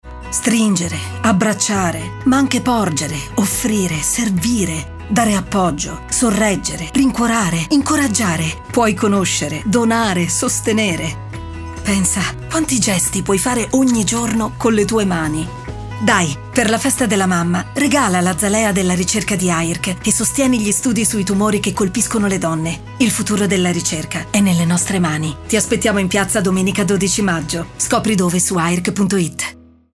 Spot audio AZALEA della Ricerca AIRC-Domenica 12 maggio 2024 Festa della Mamma